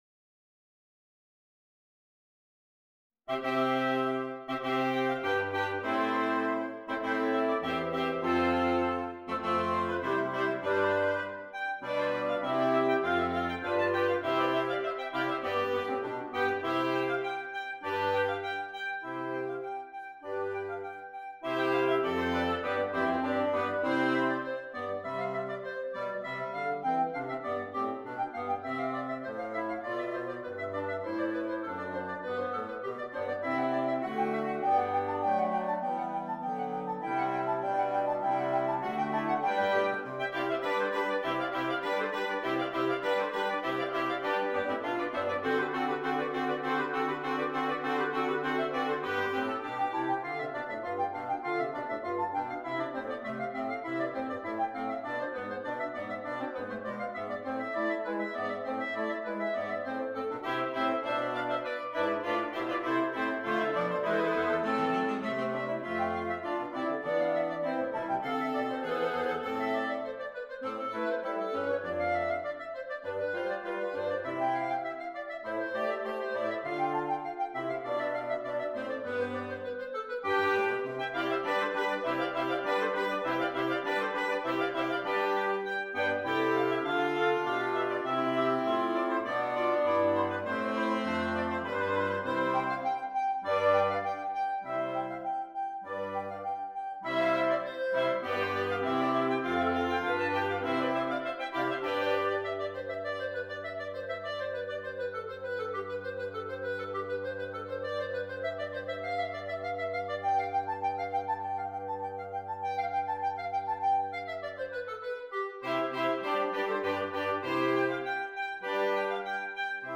Interchangeable Woodwind Ensemble
PART 1 - Flute, Clarinet, Alto Saxophone
PART 6 - Bass Clarinet, Bassoon, Baritone Saxophone